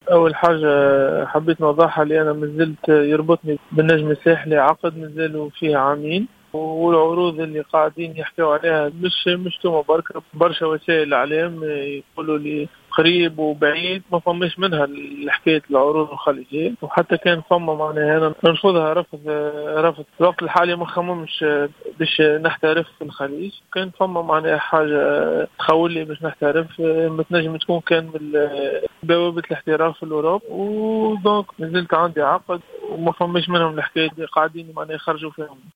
تصريح خاص براديو جوهرة أف أم